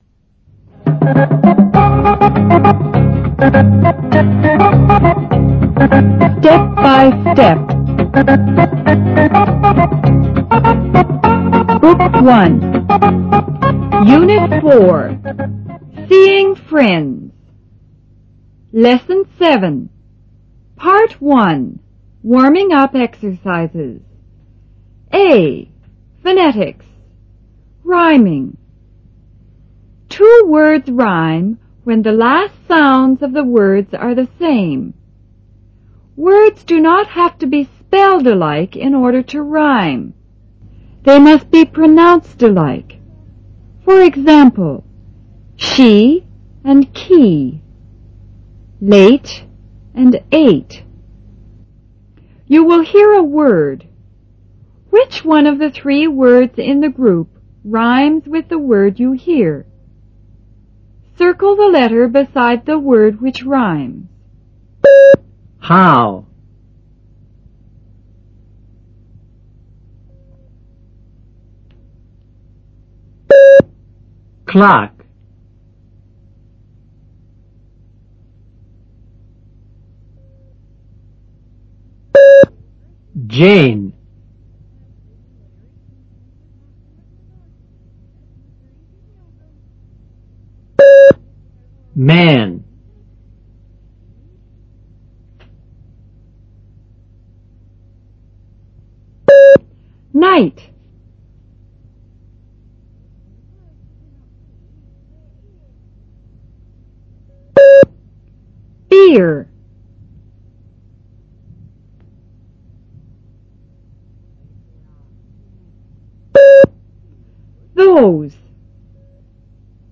A．Phonetics: Rhyming
B．Dictation